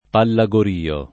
Pallagorio [ palla g or & o ] top. (Cal.)